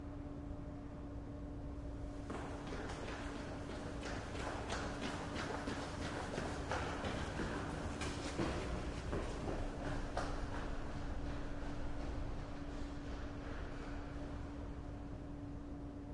描述：录下自己在一个大的混凝土地下室中奔跑的过程。立体声录音，在我跑开或跑向麦克风的时候，麦克风在一个固定的位置。索尼PCMD50
Tag: 地下室 混凝土 现场记录 脚步声 混响